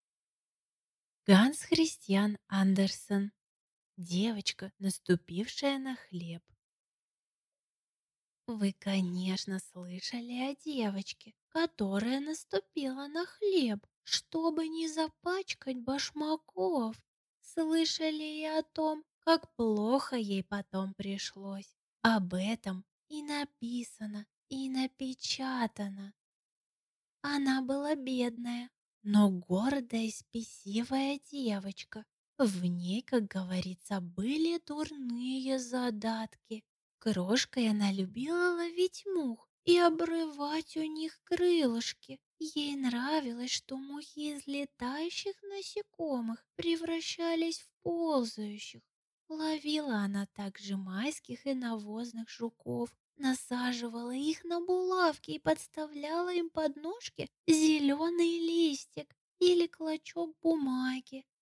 Аудиокнига Девочка, наступившая на хлеб | Библиотека аудиокниг